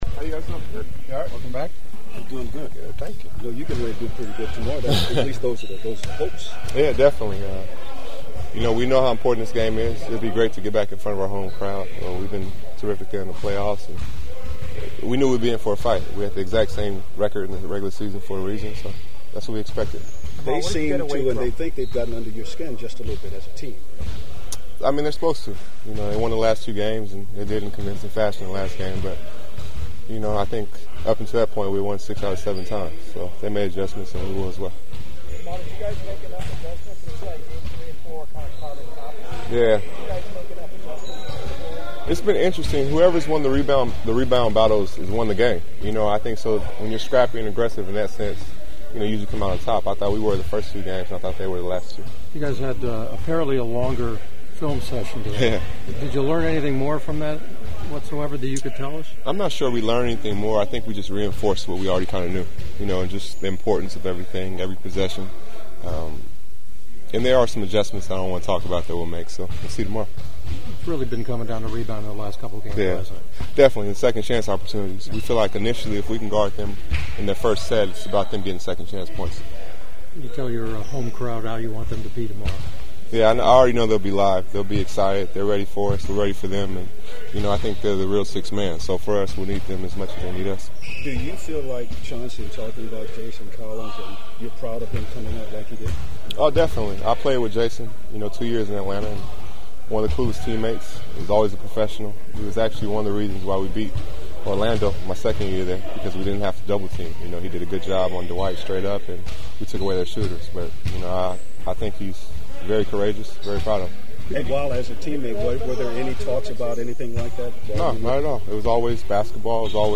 The following are our after practice chats with the Clippers…